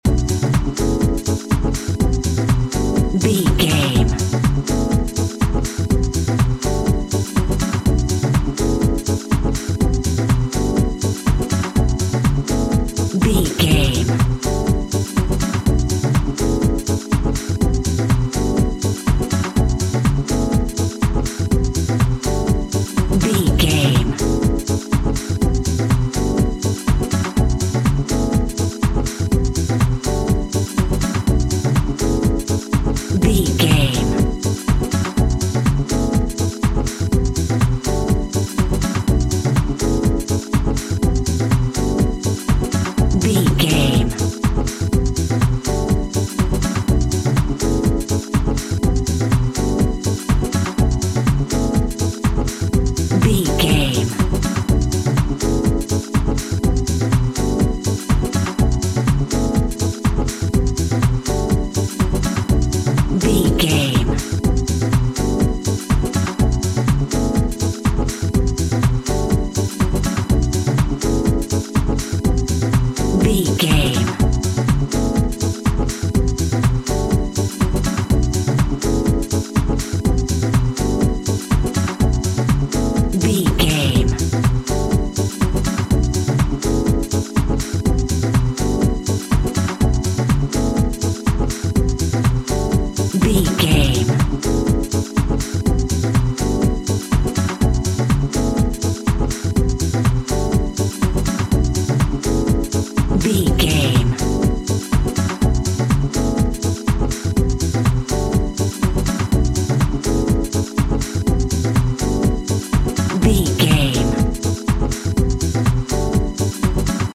Groovy Funky House Music.
Aeolian/Minor
groovy
uplifting
energetic
drums
bass guitar
electric piano
synthesiser
strings
funky house
synth bass